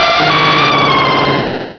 Cri de Lugia dans Pokémon Diamant et Perle.